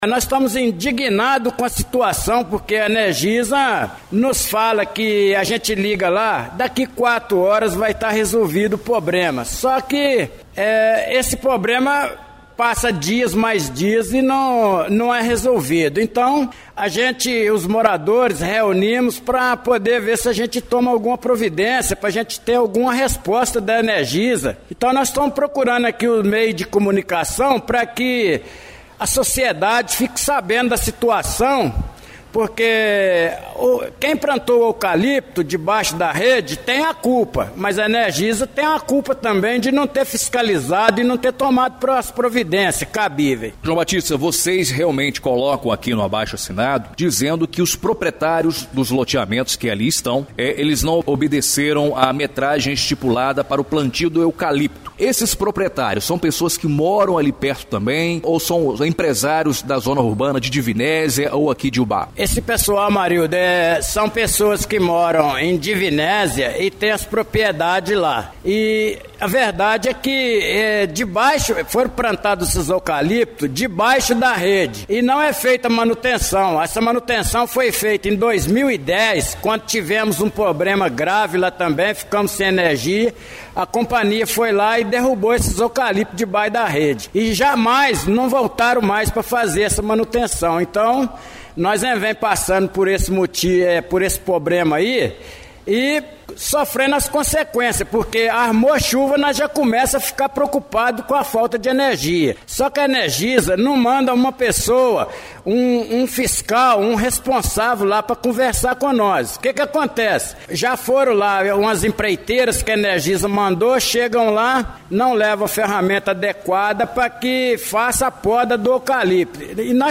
em entrevista a Rádio Educadora